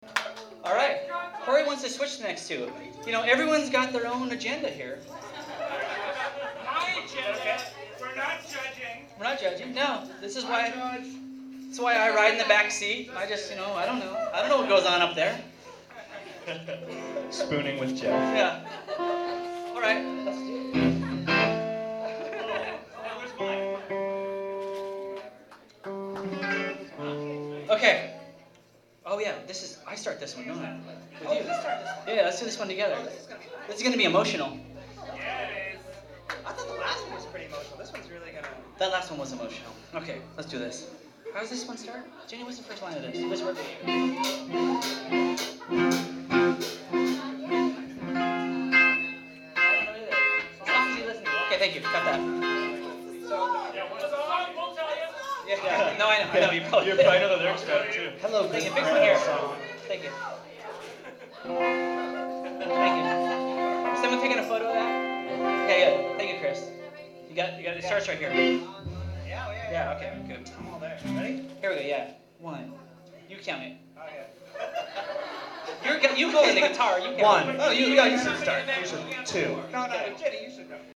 Live at PA’s Lounge
in Somerville, MA
banter
Live sets recorded with a Sony ECM-719 mic and a Sony MZ-RH10 minidisc, converted to .wav and then edited to 192kbps Mp3s.